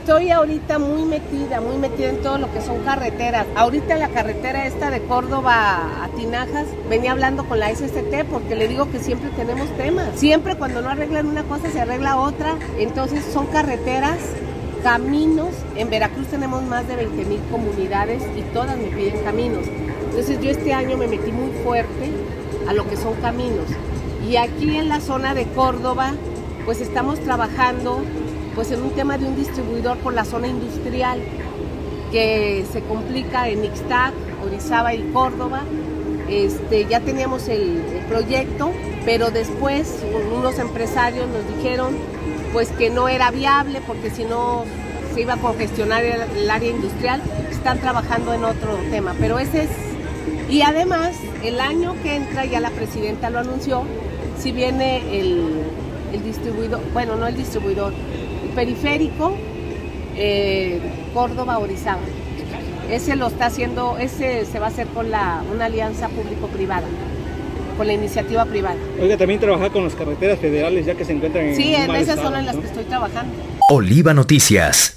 Córdoba, Ver.- No habrá obra de libramiento pero sí periférico para la zona Córdoba-Orizaba, señaló enbreveentrevista la gobernadora de Veracruz, Rocío Nahle García, en su visita a Córdoba.